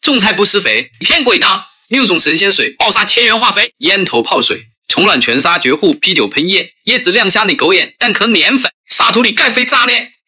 为短视频操作指南、DIY 和应用演示，打造能够让用户停止滑动的 TikTok 教程画外音，具有清晰的发音、吸引人的节奏和创作者级别的活力。
文本转语音
清晰旁白